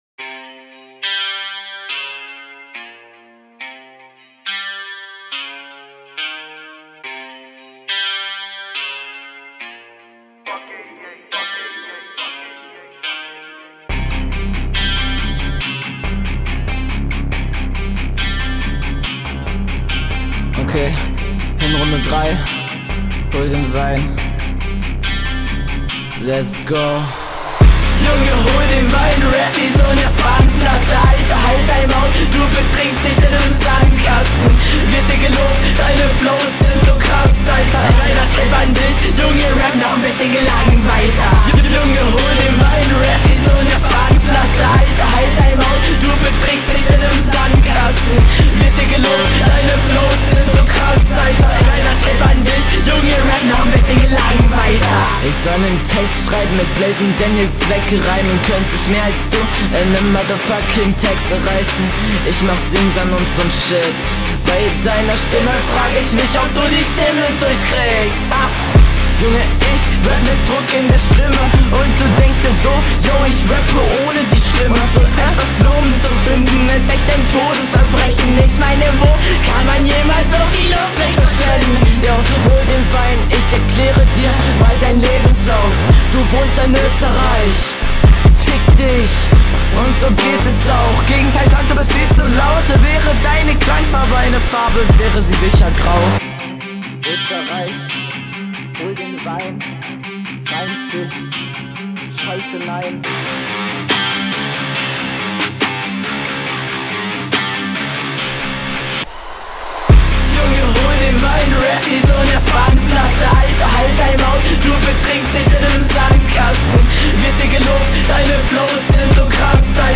Intro / Hook ist sehr sehr geil!